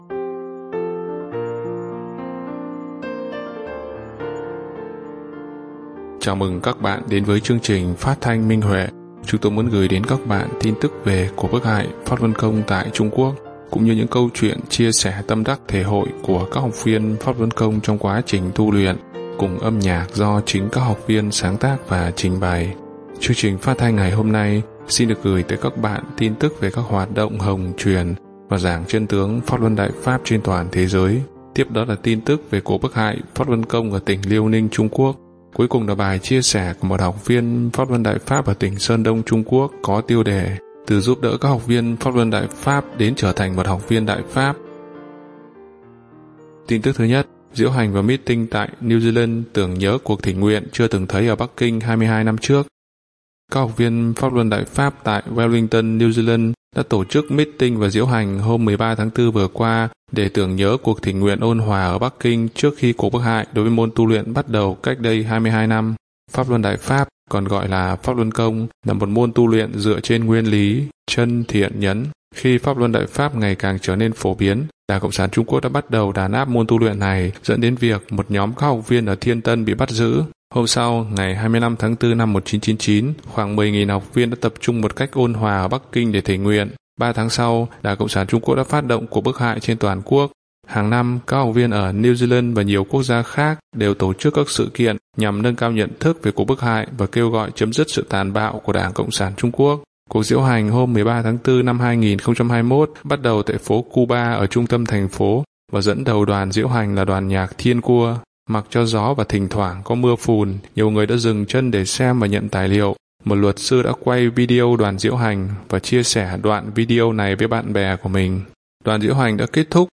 Chúng tôi muốn gửi đến các bạn tin tức về cuộc bức hại Pháp Luân Công tại Trung Quốc cũng như những câu chuyện chia sẻ tâm đắc thể hội của các học viên Pháp Luân Công trong quá trình tu luyện, cùng âm nhạc do chính các học viên sáng tác và trình bày.